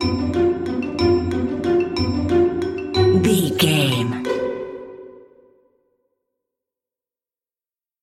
Lydian
strings
orchestra
percussion
silly
circus
goofy
comical
cheerful
perky
Light hearted
quirky